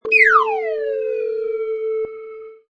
Sound production: Slide Whistle Rise 9
Rising sound made by a slide whistle, good for cartoons and animation
Product Info: 48k 24bit Stereo
Category: Musical Instruments / Slide Whistle
Try preview above (pink tone added for copyright).
Slide_Whistle_Rise_9.mp3